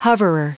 Prononciation du mot hoverer en anglais (fichier audio)
Prononciation du mot : hoverer